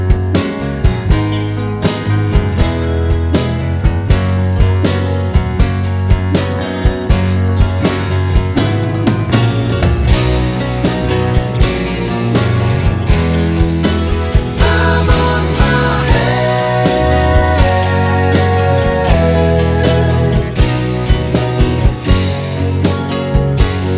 No Piano Pop (1970s) 4:45 Buy £1.50